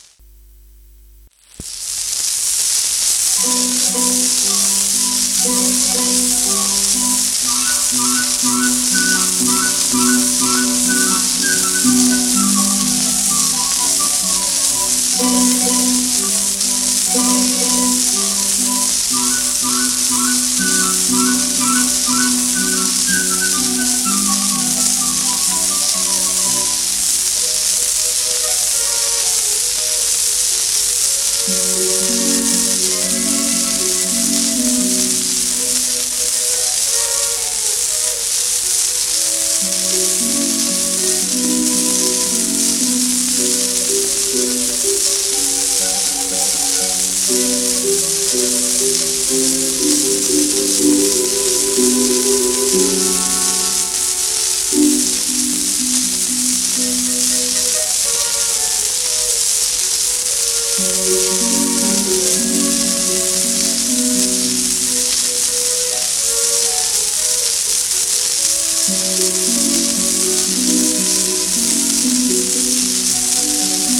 盤質A- *シェラックノイズ